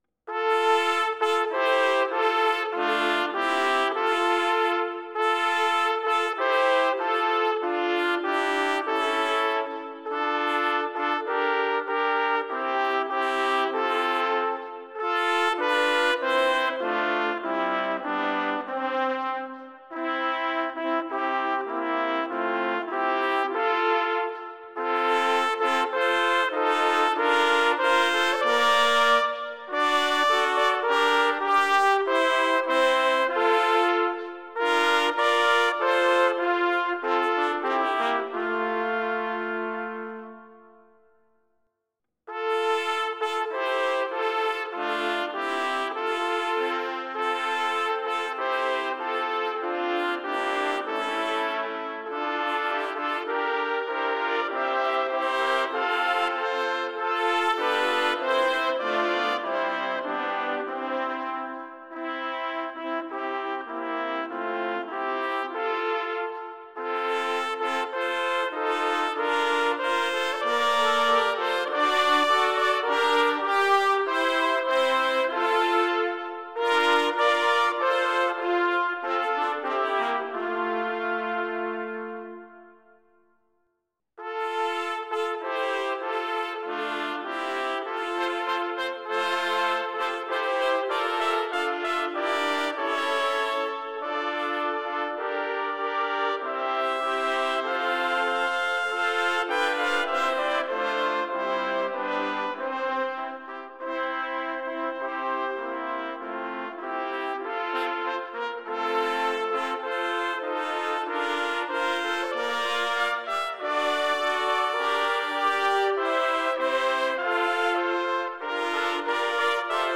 Number of Trumpets: 3
Exhilarating, majestic, and inspiring.